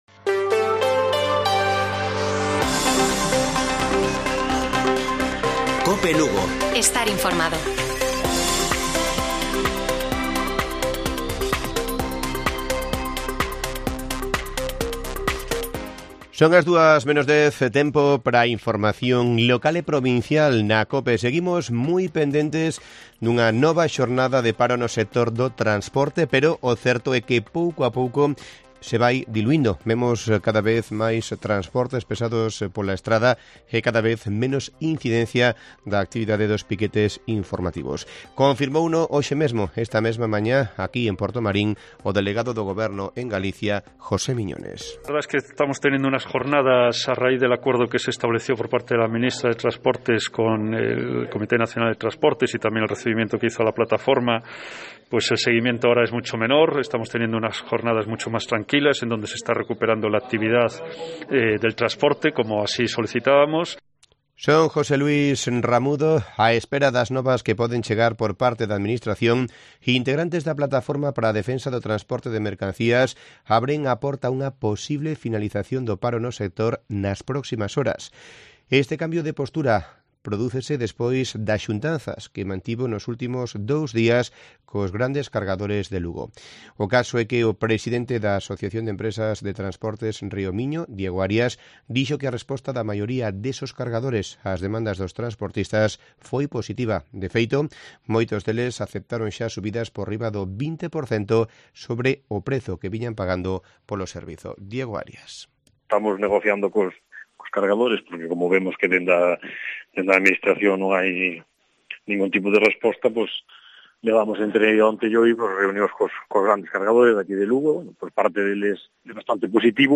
Informativo Mediodía de Cope Lugo. 29 de marzo. 13:50 horas